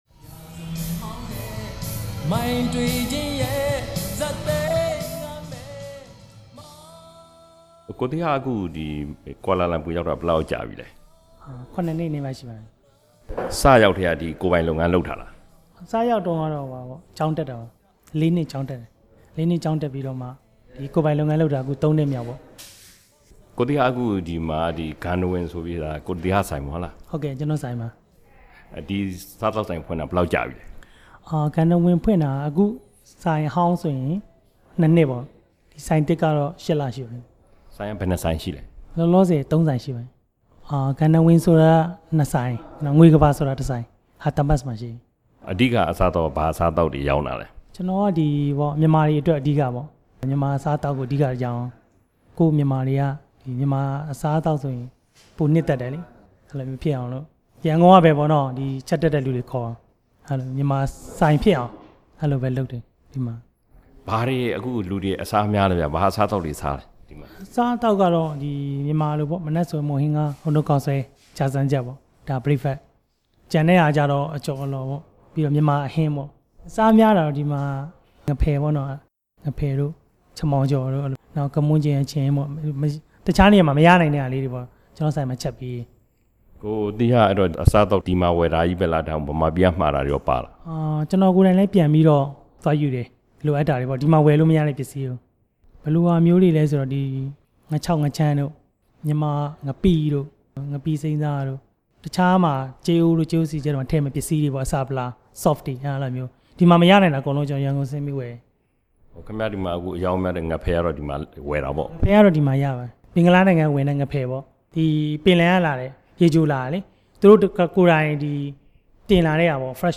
မလေးရှားမှာ စားသောက်ဆိုင်ဖွင့်ပြီး အောင်မြင်နေတဲ့ မြန်မာလုပ်ငန်းရှင်တစ်ဦးနှင့် မေးမြန်းချက်